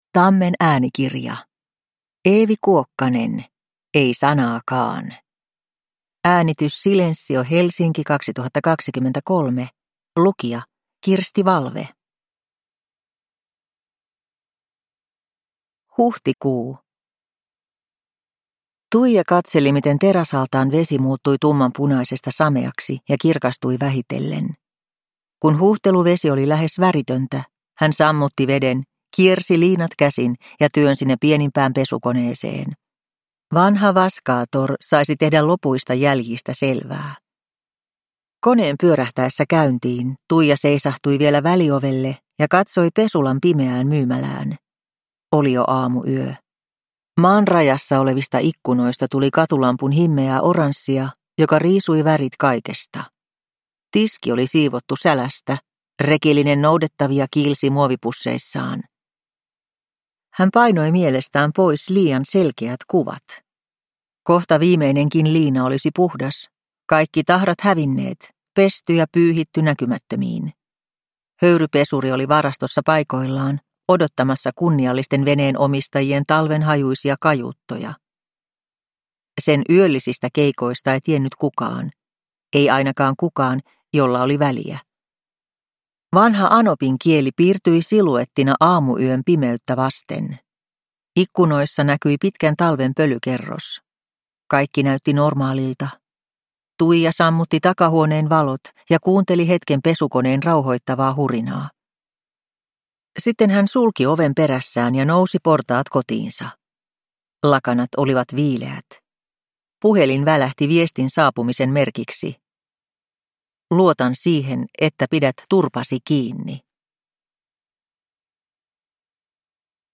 Ei sanaakaan – Ljudbok – Laddas ner